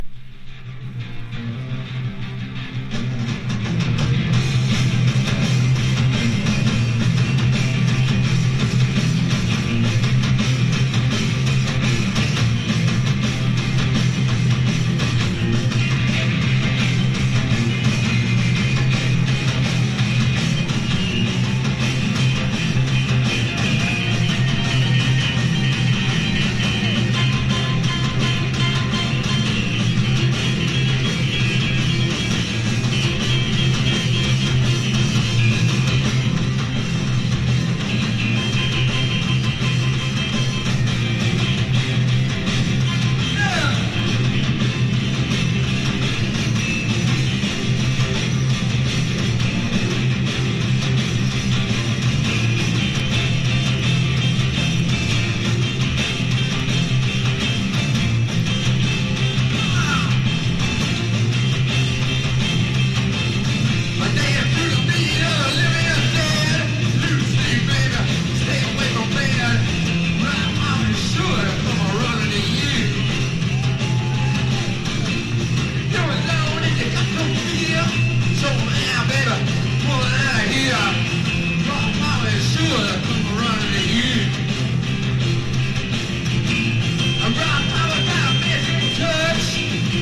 ロックの歴史に残るライヴ盤。
70’s ROCK